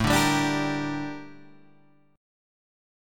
Gm/A chord